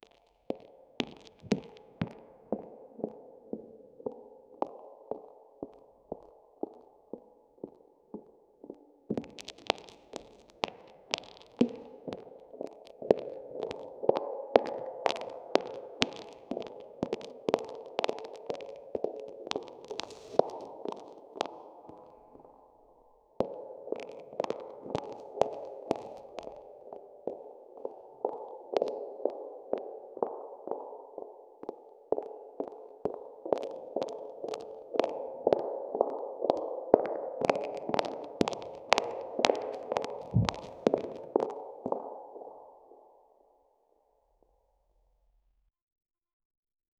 amb_library.mp3 - Przewracanie pergaminów, ciche kroki, echo
amblibrarymp3---przewraca-xtc7stgj.wav